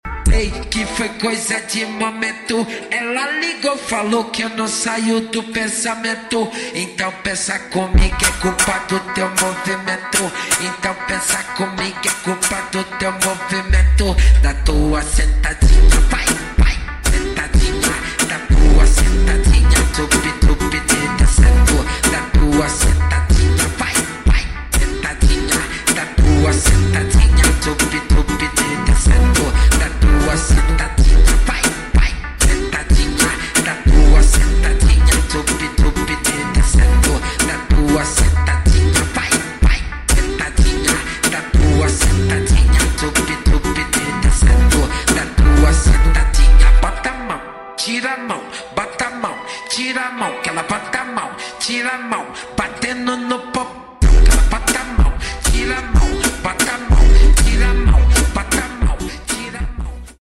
#8daudio